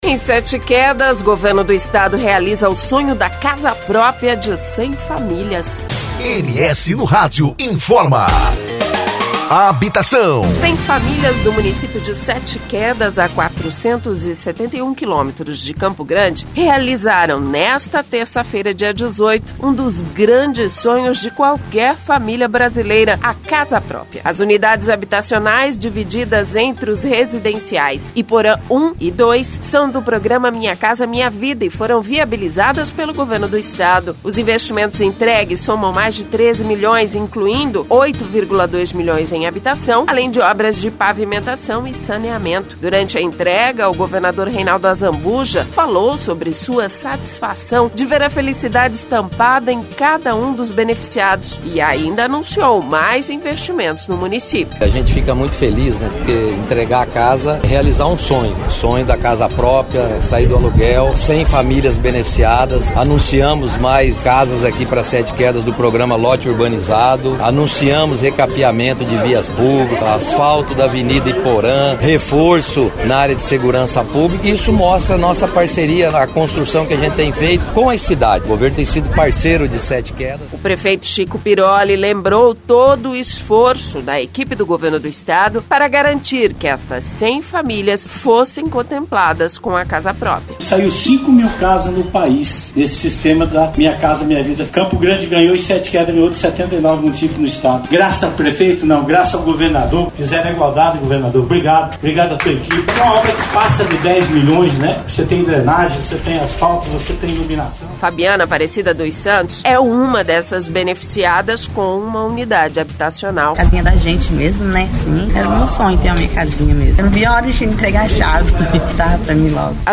Durante a entrega o governador Reinaldo Azambuja falou sobre a satisfação de ver a felicidade estampada em cada um dos beneficiados, e ainda anunciou mais investimentos no município.
O prefeito Chico Piroli lembrou todo o esforço da equipe do governo do Estado para garantir que estas cem famílias fossem contempladas com a casa própria.